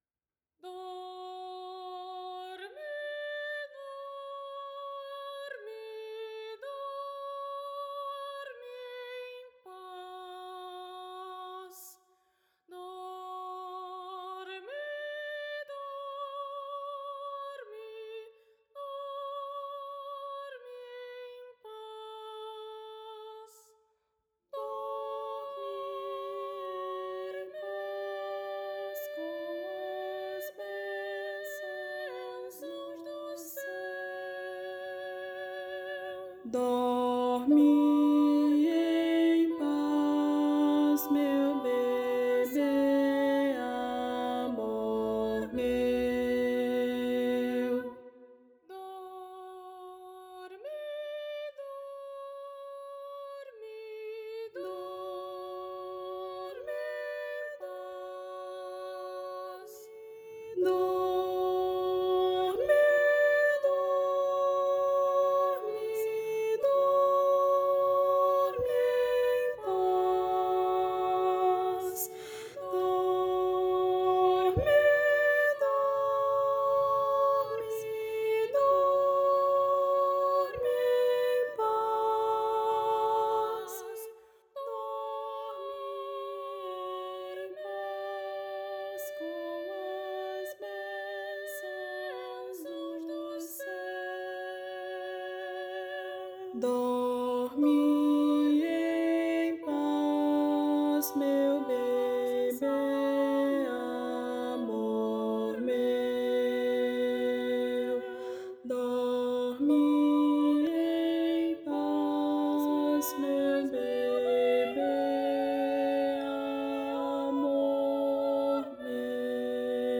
para coro infantil a três vozes
uma singela canção de ninar
Voz Guia 3